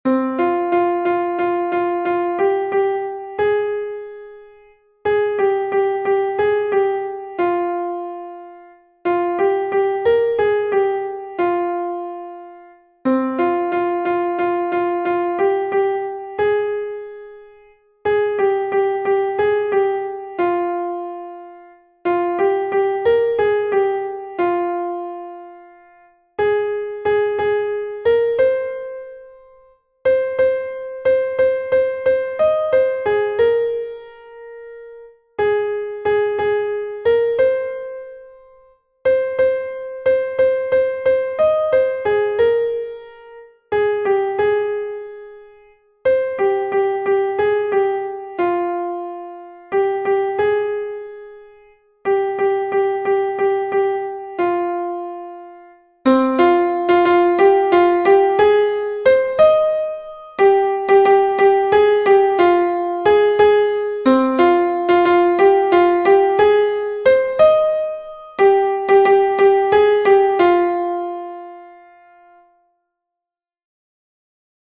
Fichier son soprano 2
Nerea-izango-zen-Laboa-soprano-2-V3-1.mp3